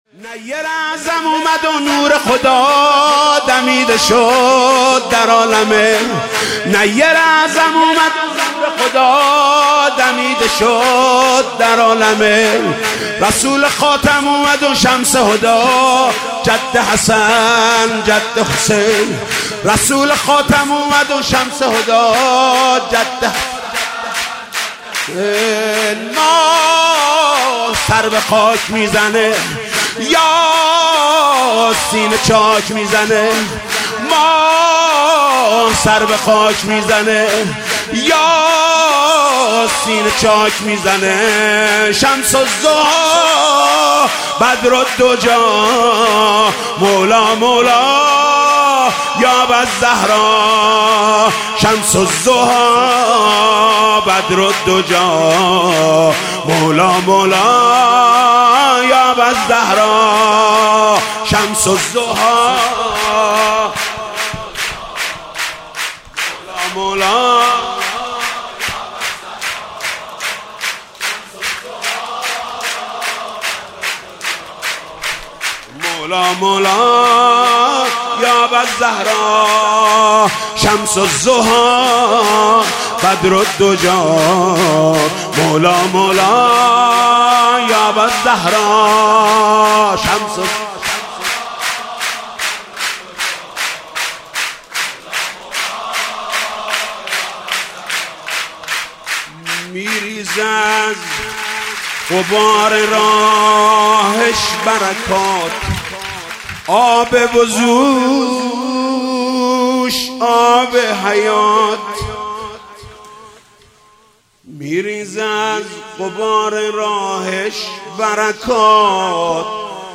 مولودی